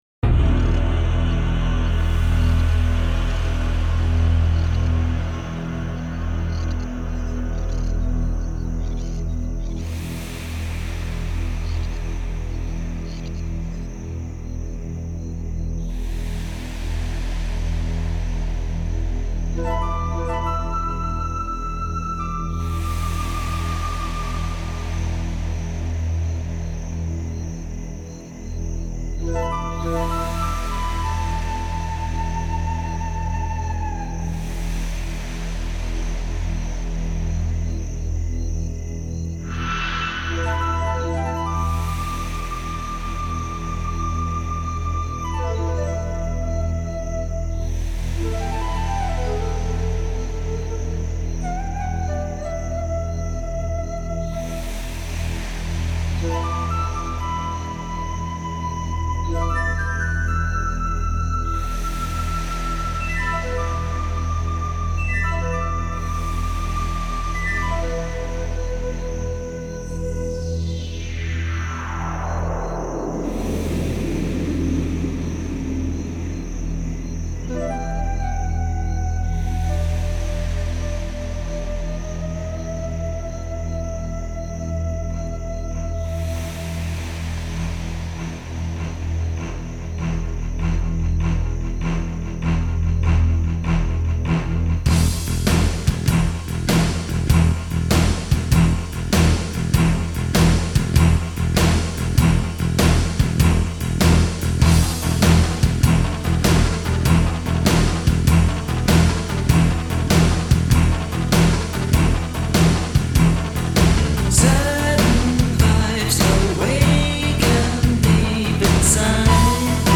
Progressive Rock / Space Rock / Art Rock